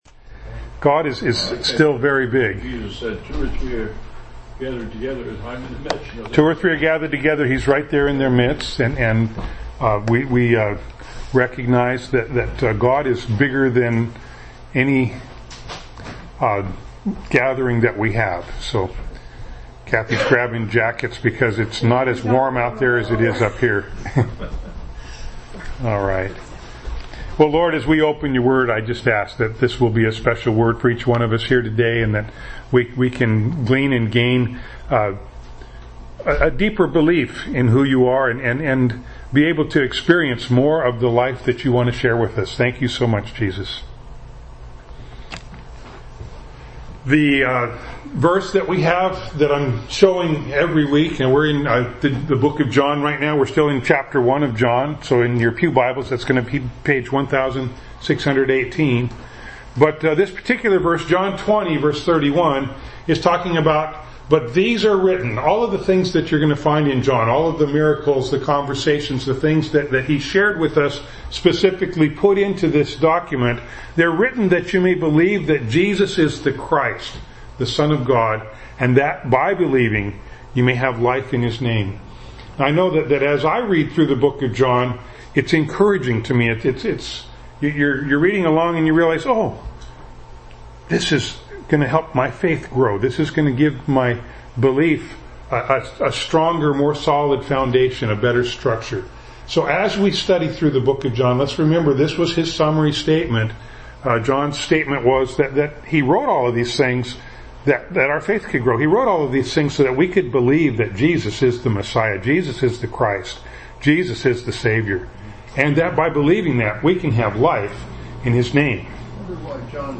John 1:14-18 Service Type: Sunday Morning Bible Text